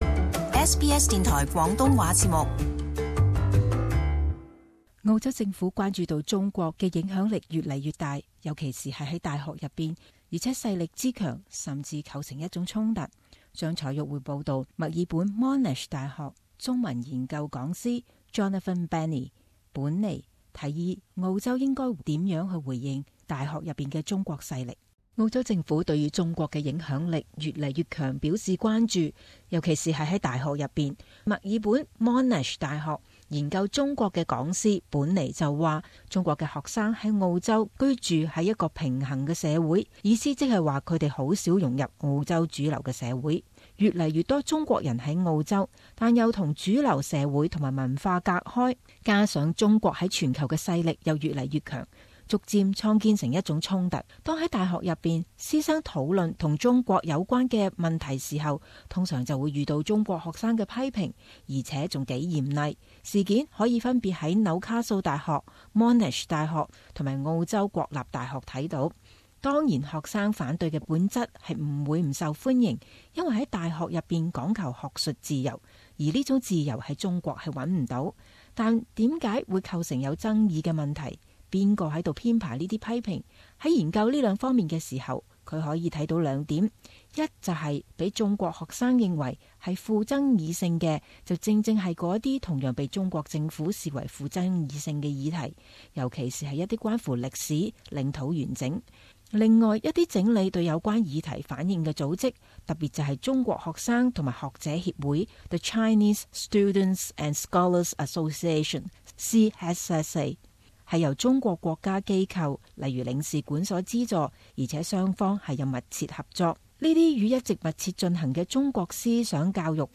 【時事報導】澳洲大學的中國勢力